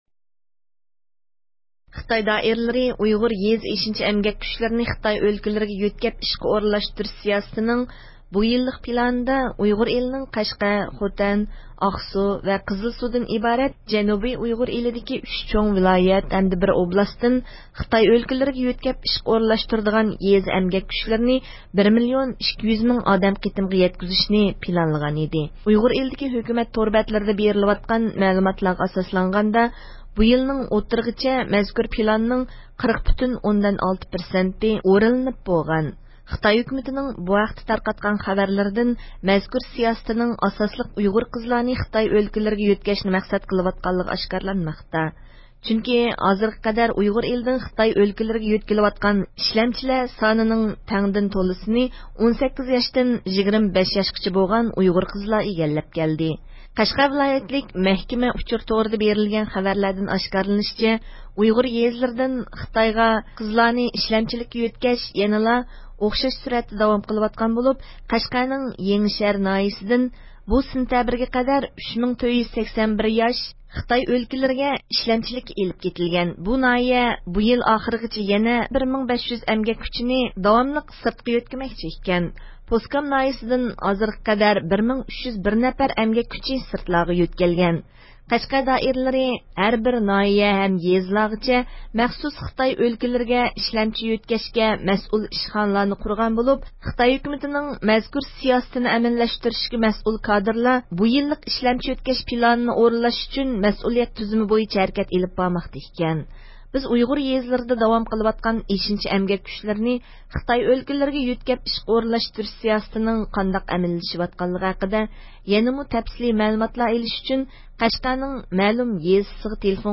پوسكام ناھىيىلىك پارتكوم سېكرىتارى chen xuguang ، سوئاللىرىمىزغا جاۋاب بېرىش جەريانىدا خىتاي ھۆكۈمىتىنىڭ مەزكۇر سىياسىتىنى مۇددەتسىز داۋام قىلدۇرۇشنى پىلانلىغانلىقىنى ئاشكارىلىدى: